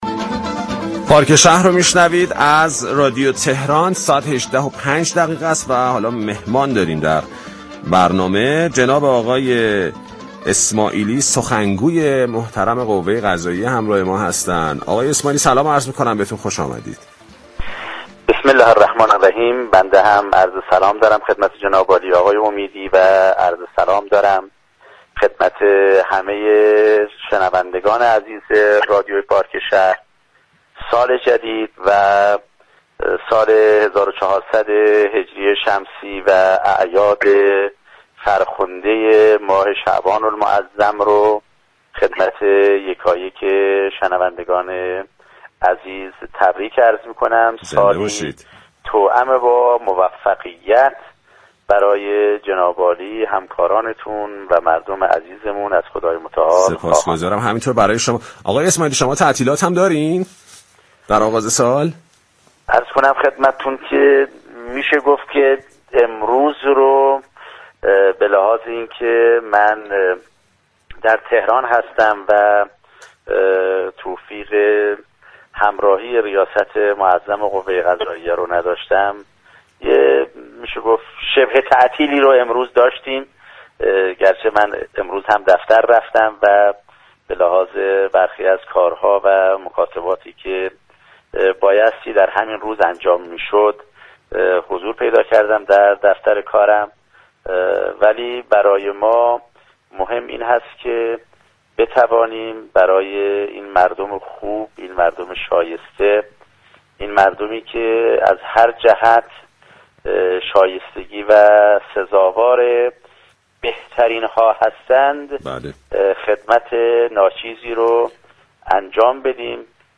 به گزارش پایگاه اطلاع رسانی رادیو تهران، غلامحسین اسماعیلی سخنگوی قوه قضاییه در گفتگو با اولین برنامه پارك شهر در سال نو ضمن تبریك اعیاد شعبانیه و عید باستانی نوروز گفت: روز اول فروردین را در تهران مانده ام و توفیق همراهی رئیس قوه قضاییه را نداشتم اما به لحاظ كارهایی كه باید انجام می شد در دفتر كارم حضور دارم اما خدمت به مردم و جامعه افتخاری برای ما است و به همین دلیل دنبال تعطیلی و استراحت نیستیم و نخواهیم بود.